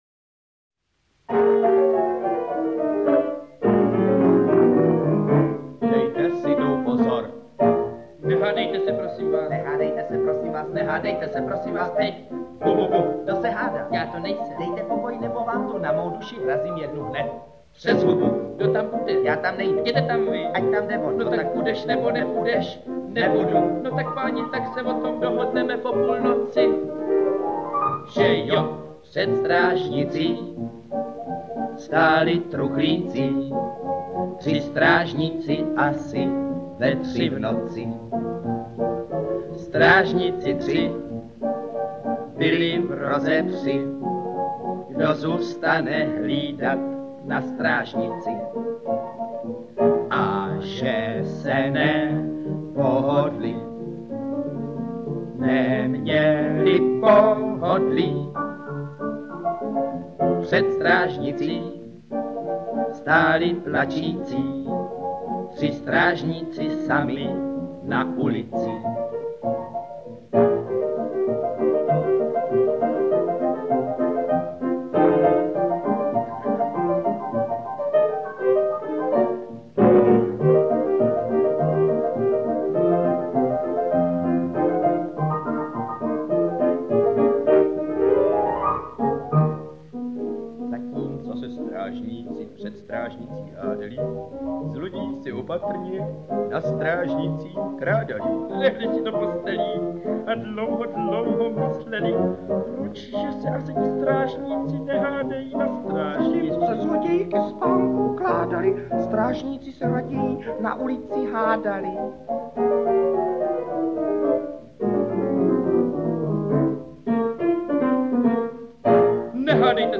Historický, přesto však humorně podaný dokumentární cyklus obsahující nahrávky her Osvobozeneckého divadla v časové posloupnosti. O zábavu se postarají Jiří Voskovec, Jan Werich a Jaroslav Ježek.
Čte: Jan Werich, Jiří Voskovec